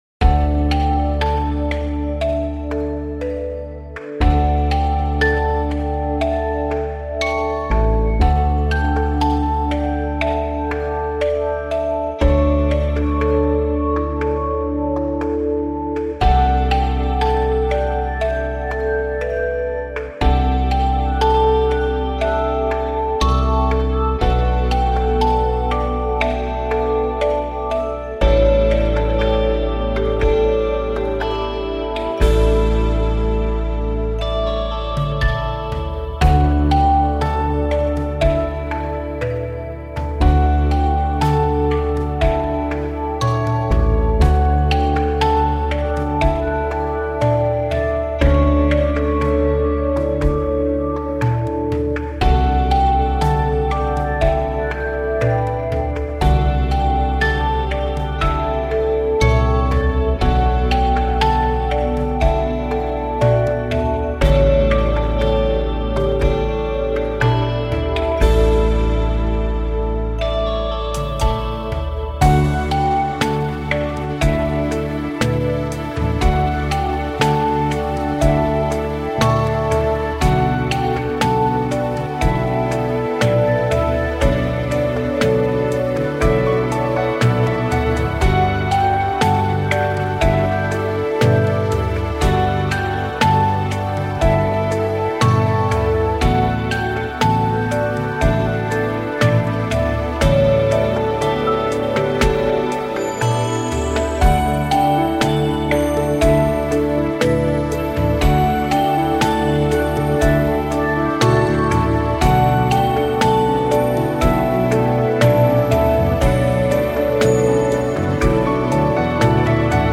最初はコミカルっぽいですが、徐々に音が重なり充電されていきます。